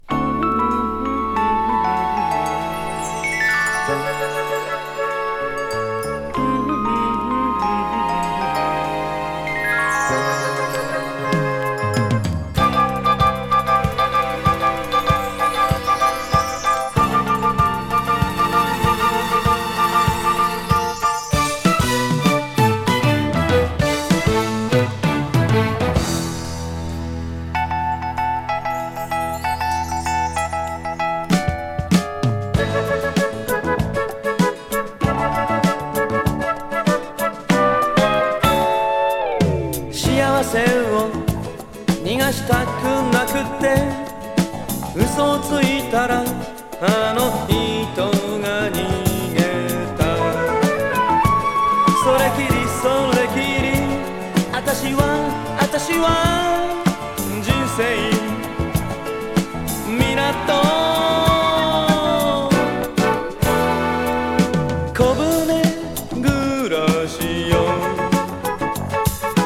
スムースメロウ〜AOR感触でGood。